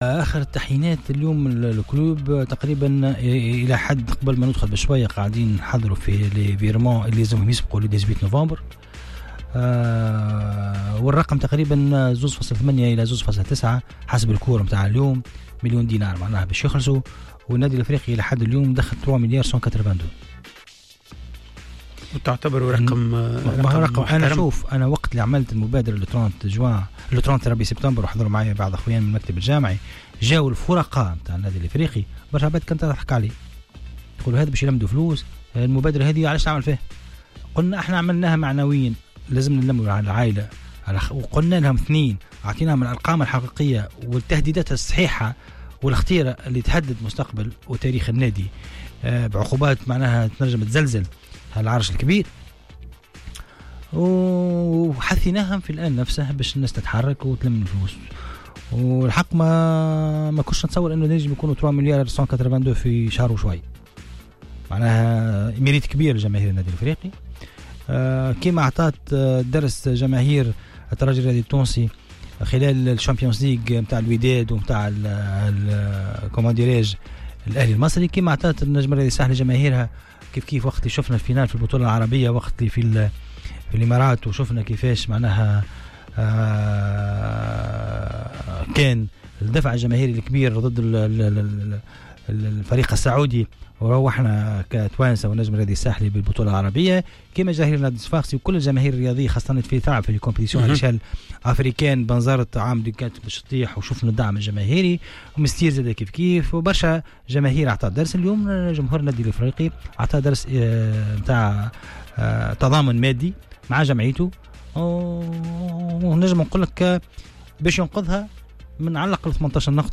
وديع الجريء : رئيس الجامعة التونسية لكرة القدم تحميل المشاركة علي فيديو